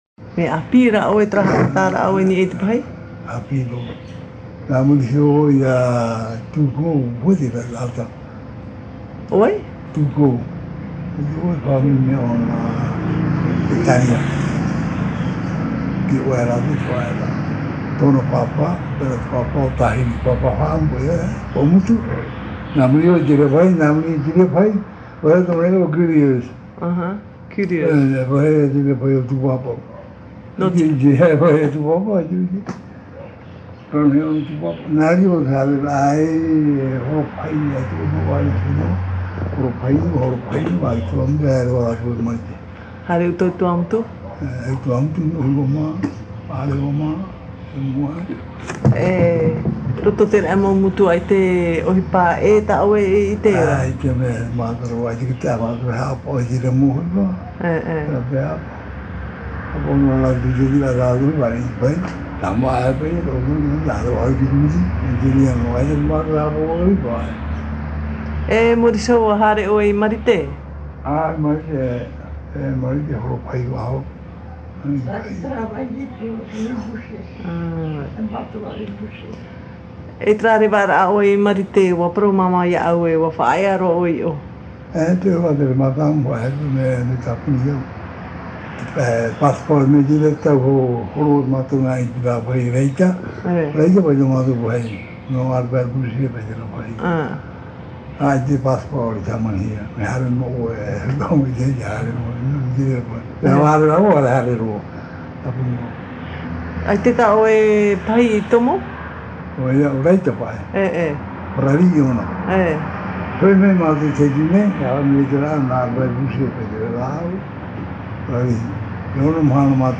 Interview réalisée à Pape'ete sur l’île de Tahiti.
Papa mātāmua / Support original : cassette audio